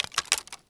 wood_tree_branch_move_06.wav